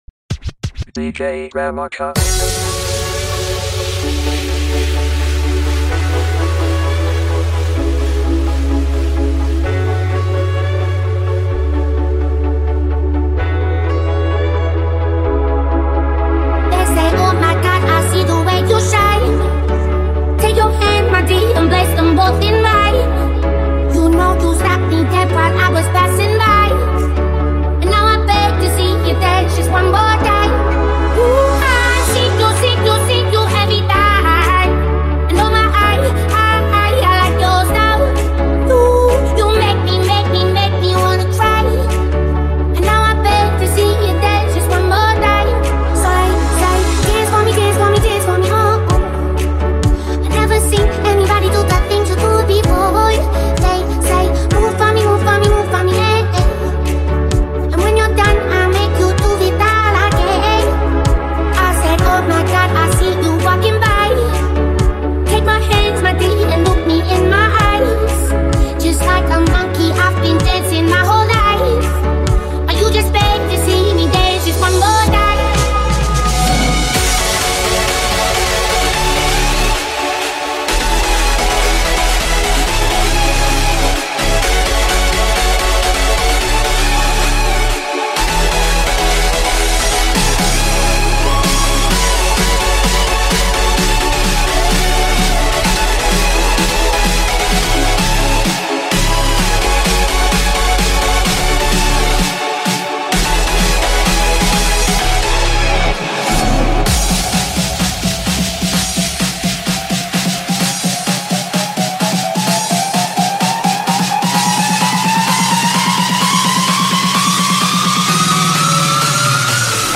EDM to Hardstyle Remake